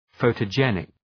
Προφορά
{,fəʋtə’dʒenık}